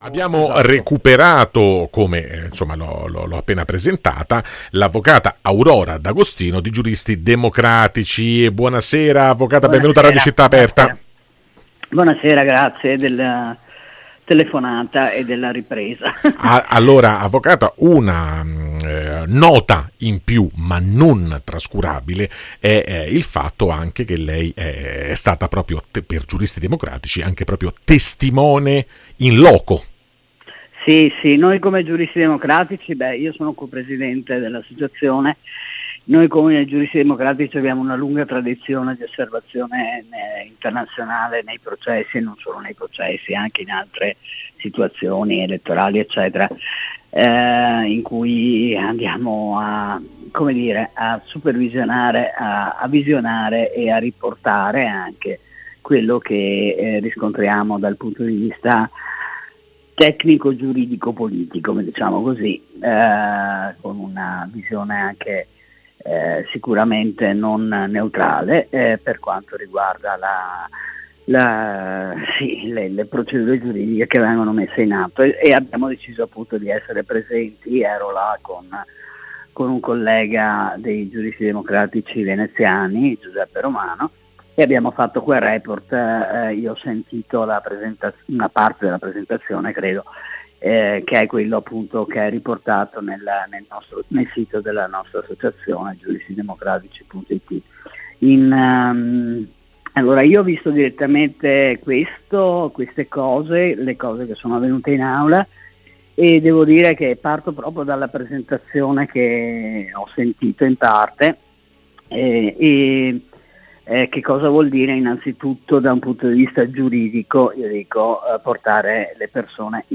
la trasmissione di approfondimento giornalistico di Radio Città Aperta